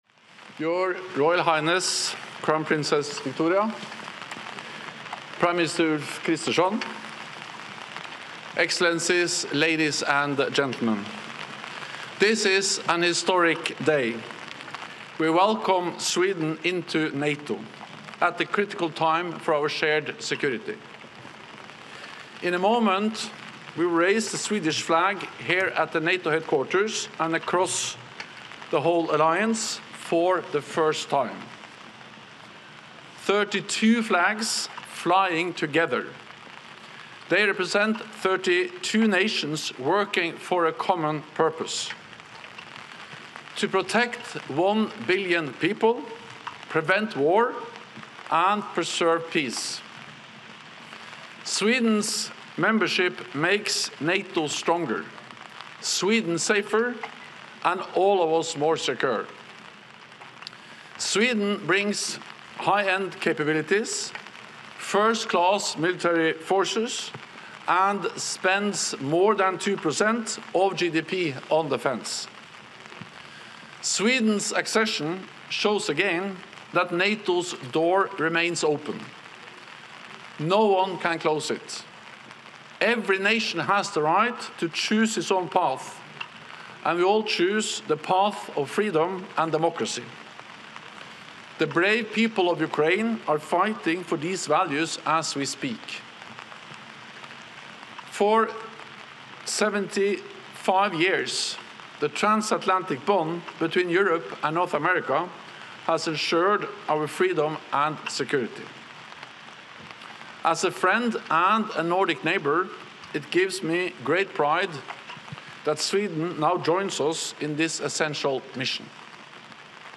Joint Remarks at Flag Raising Ceremony Signifying Sweden's Membership in NATO
delivered 11 March 2024, NATO HQ, Brussels, Belgium